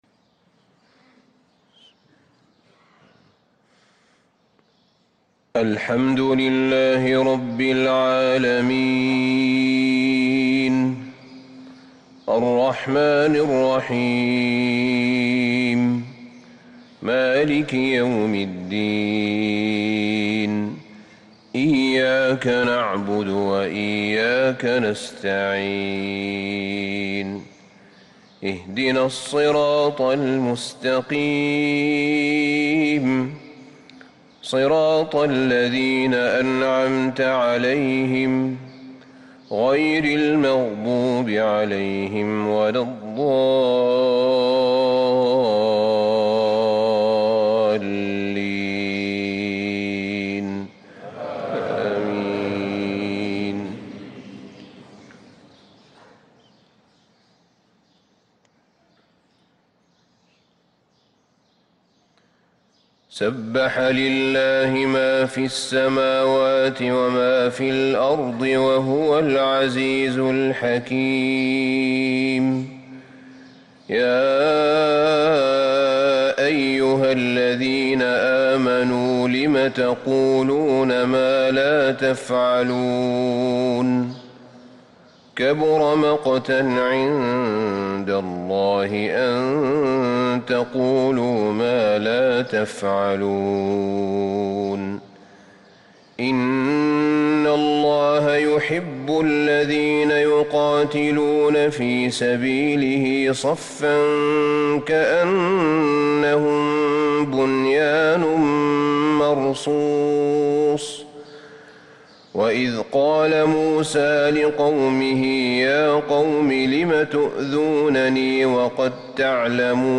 صلاة الفجر للقارئ أحمد بن طالب حميد 29 جمادي الأول 1445 هـ
تِلَاوَات الْحَرَمَيْن .